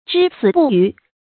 之死不渝 注音： ㄓㄧ ㄙㄧˇ ㄅㄨˋ ㄧㄩˊ 讀音讀法： 意思解釋： 同「之死靡它」。